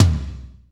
TOM F S L10L.wav